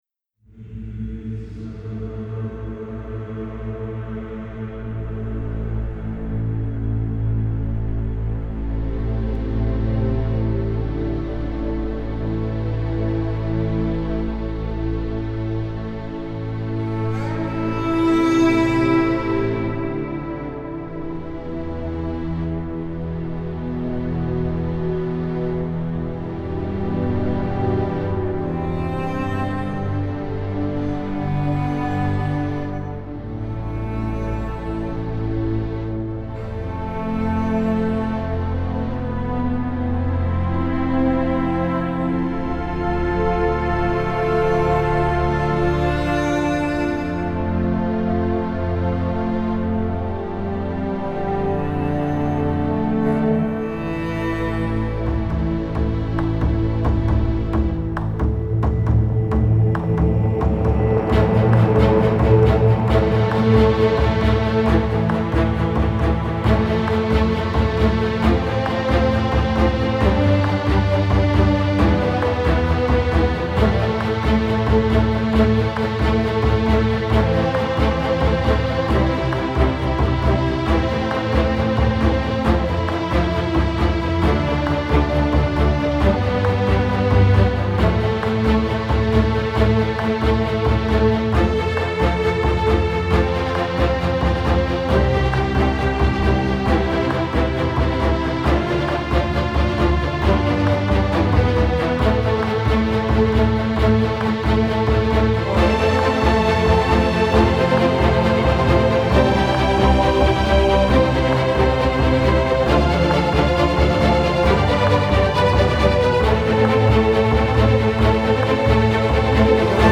اثرى حماسى یا Epic
موسیقی بی‌کلام – موسیقی نیو ایج (اپیک – آمبینت) تاریخ انتشار